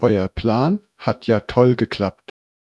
sample04-hifigan.wav